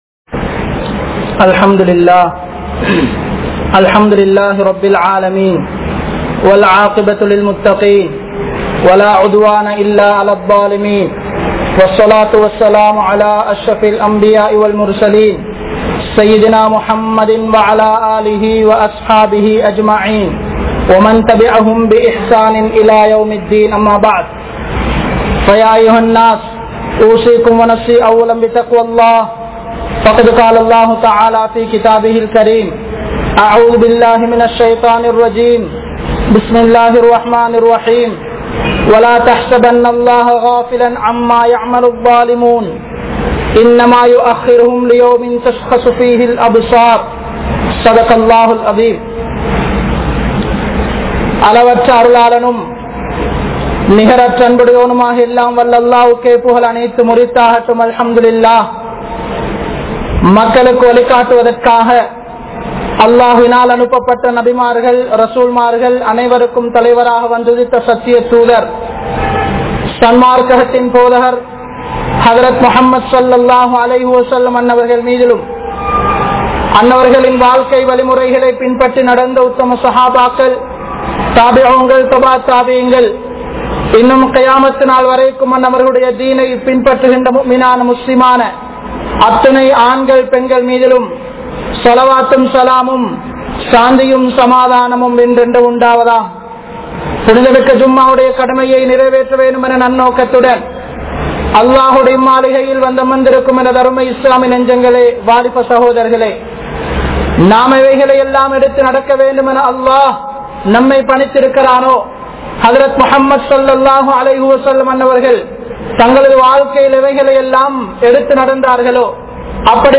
Aniyaayam (அநியாயம்) | Audio Bayans | All Ceylon Muslim Youth Community | Addalaichenai
Saliheen Jumua Masjidh